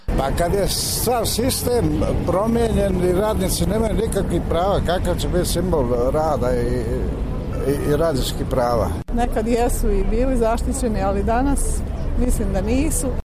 Banjalučani o Prvom maju